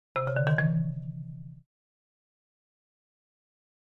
Marimba, Short Ascending, Type 1